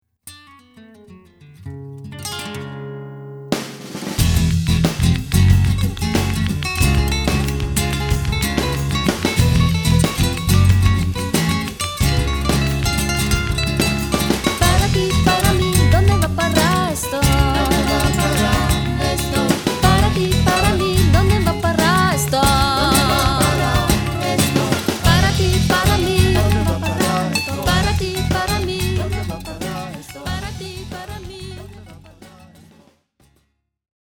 Akustik-Gitarre, Gesang, Palmas
Cajon, Gesang, Palmas
Flamenco-Gitarre, Cajon, Gesang
• Latin/Salsa/Reggae